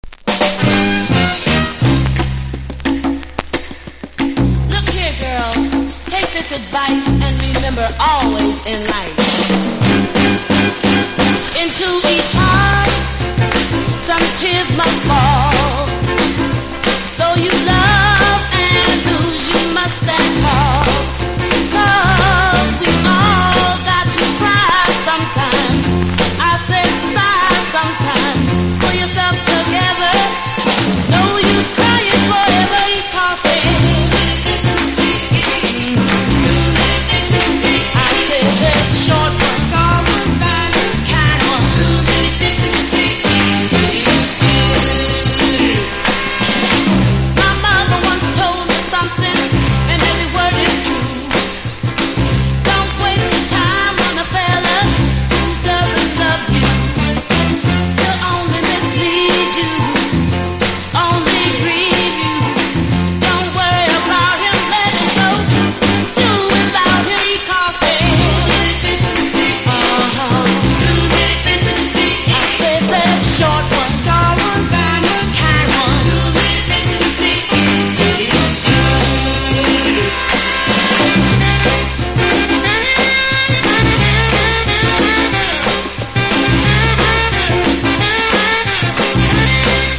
所によりノイズありますが、リスニング用としては問題く、中古盤として標準的なコンディション。
軽快なガールズ・ポップ名曲。